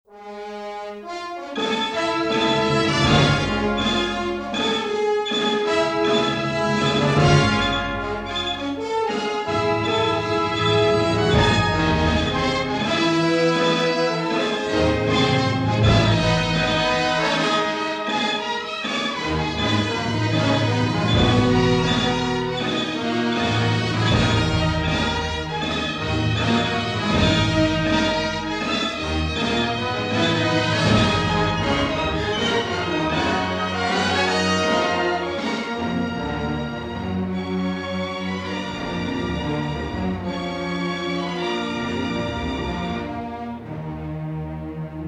remixed from the original six-track masters.